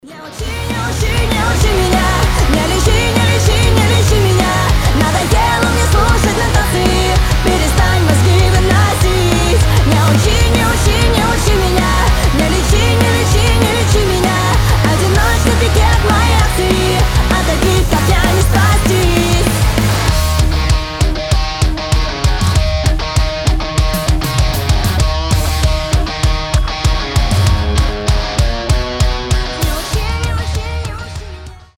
• Качество: 320, Stereo
громкие
Драйвовые
панк-рок
поп-панк